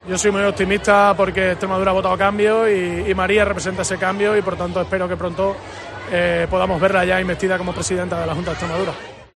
Gragera, a preguntas de los medios en un acto en Badajoz, ha pedido “calma y paciencia”, y ha insistido en que “Guardiola representa el cambio, y será presidenta de la comunidad, pues así lo han querido de forma mayoritaria los extremeños”.